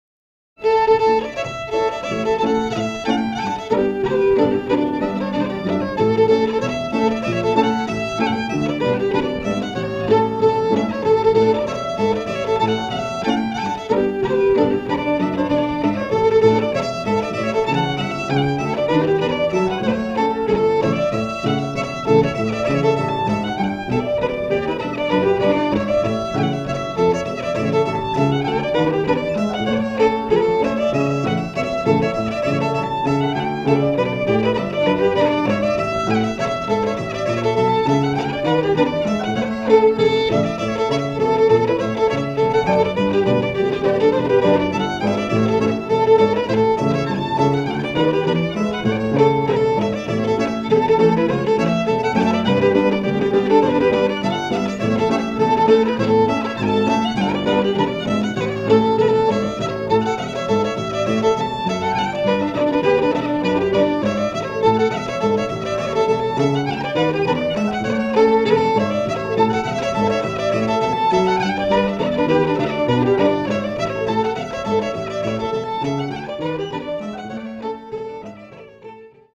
20th century Scottish pipe tune